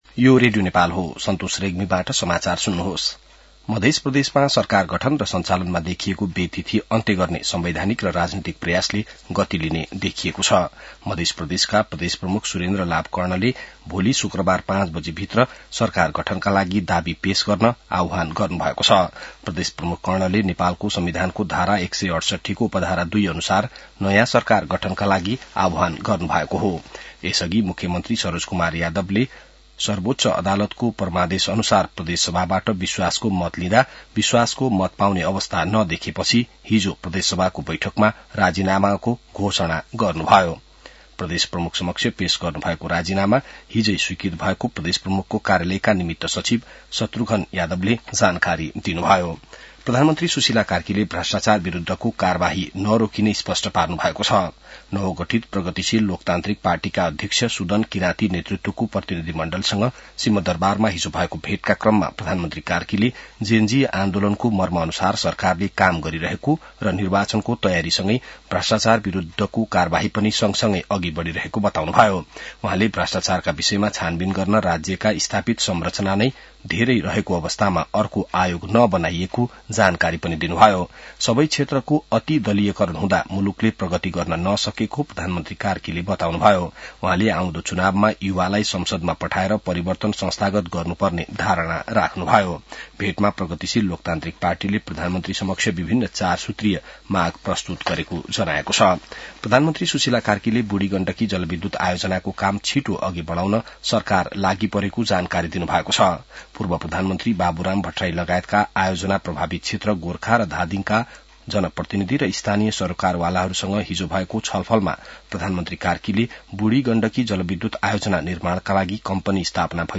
बिहान ६ बजेको नेपाली समाचार : १८ मंसिर , २०८२